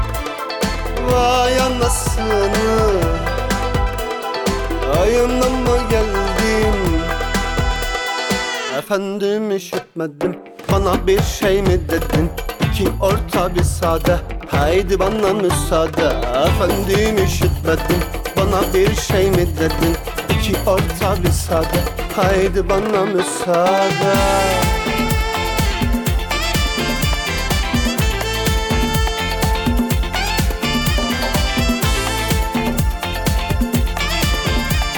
Pop Turkish Pop
Жанр: Поп музыка